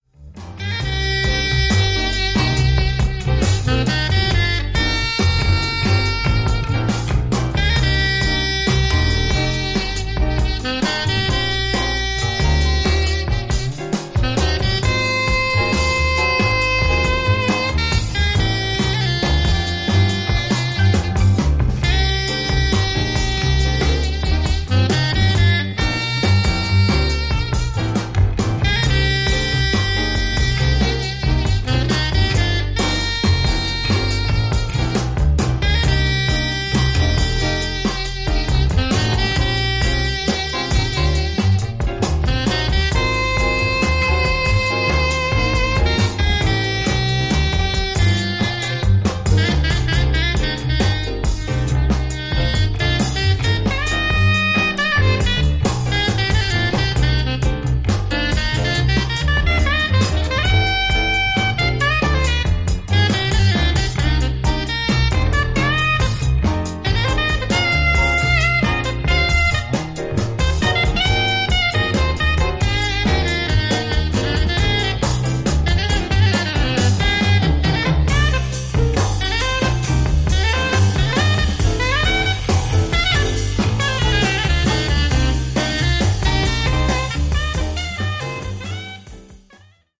スピリチュアルな雰囲気も漂う